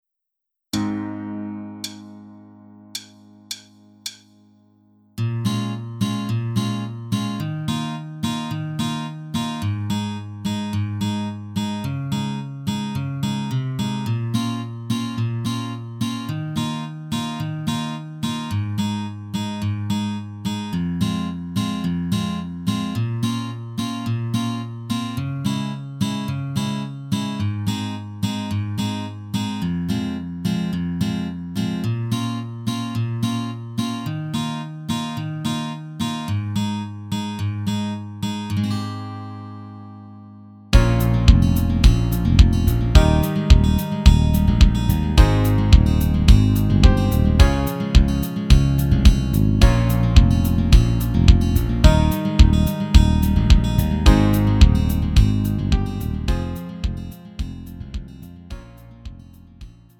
미리듣기
음정 원키
장르 가요 구분 Lite MR